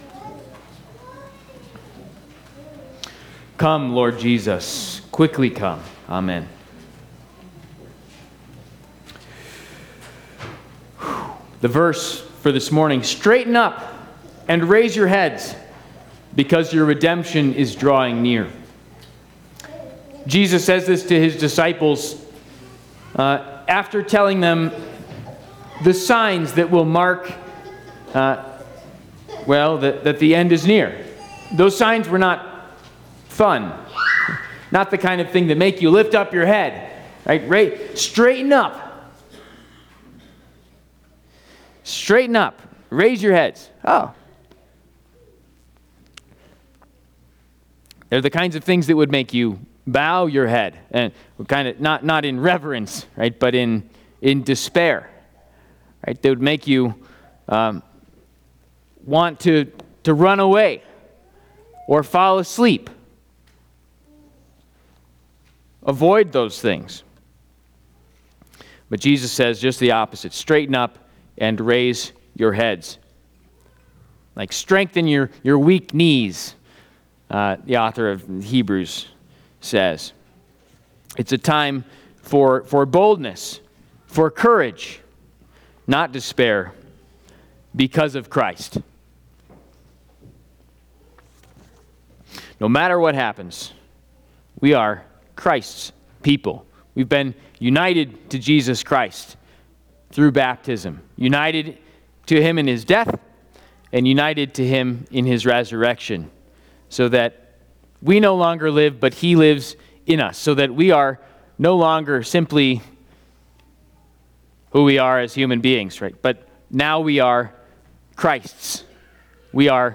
Trinity Lutheran Church, Greeley, Colorado Straighten Up and Raise Your Heads!